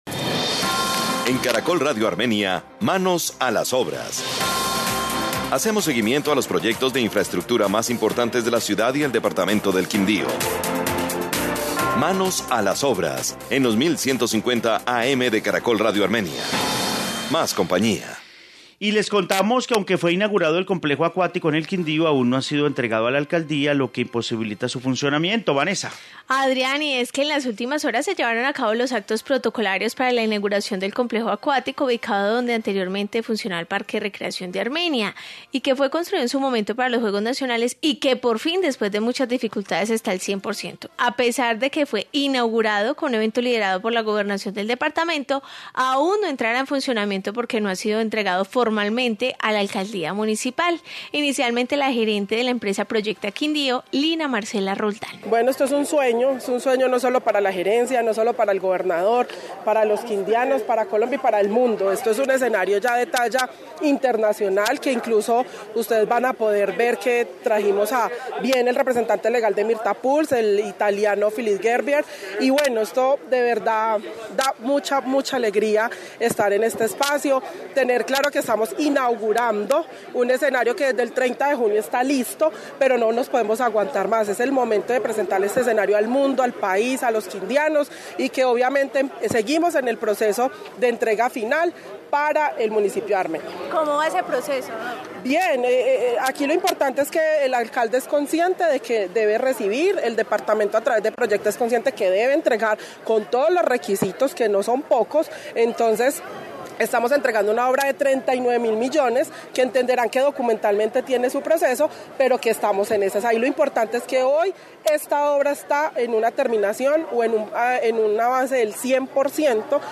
Informe sobre complejo acuático